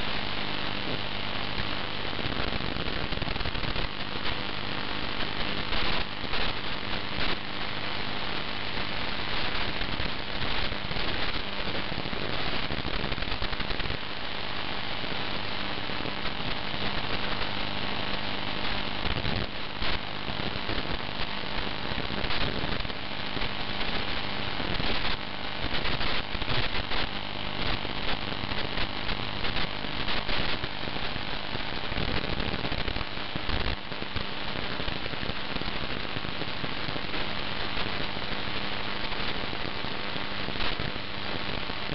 AMAmplitude Modulation demodulated sample of LTE-TDD captured on Chinese spyserver
LTE-TDD-sound.wav